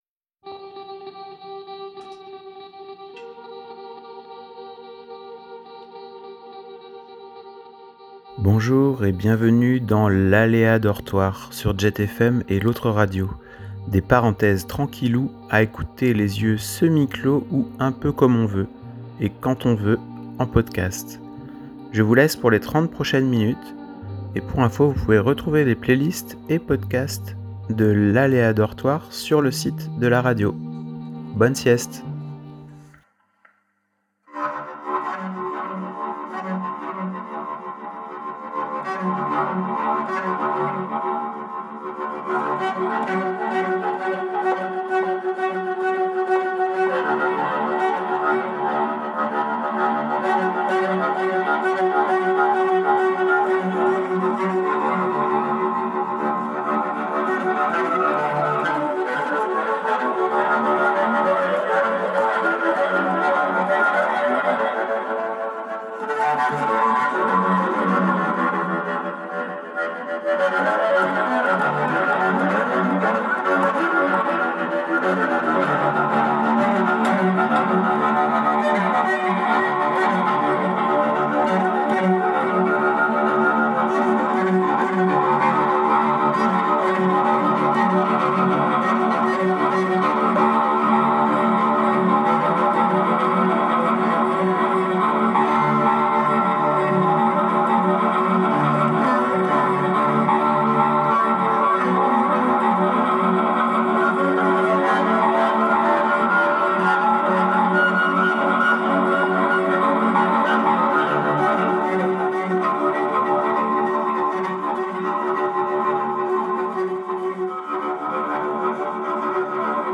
L'Aléatoire, une émission musicale sur l'Autre Radio : rock rocambolesque, chansons acrobates, jazz désaxé, techno biscornue,... A moins que ce ne soit l'inverse?!Une émission diffusée depuis 2015 sur l'Autre Radio et sur Jet FM depuis 2019.